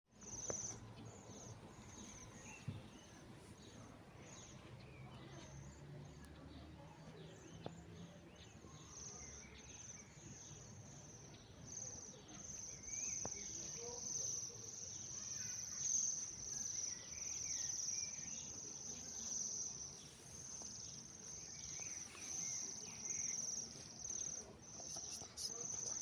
Waxwing, Bombycilla garrulus
Administratīvā teritorijaĶekavas novads
Count40 - 60